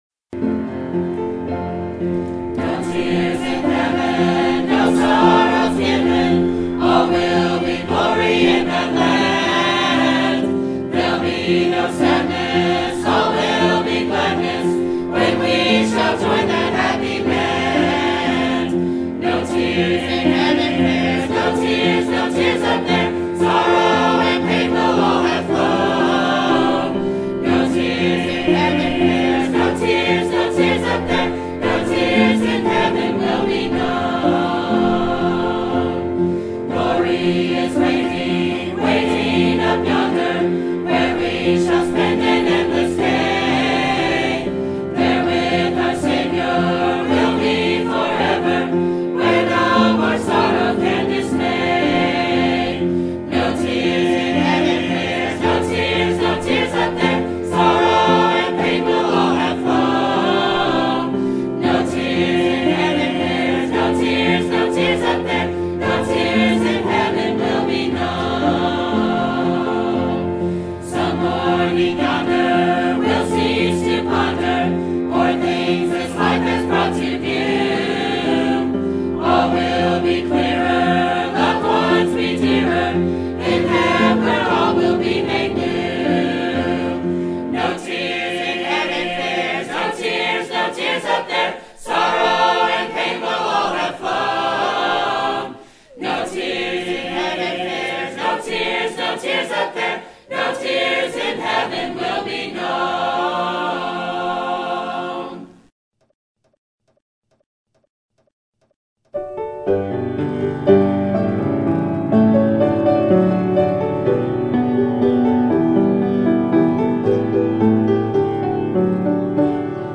Sermon Topic: General Sermon Type: Service Sermon Audio: Sermon download: Download (26.88 MB) Sermon Tags: Genesis Enoch Walking Living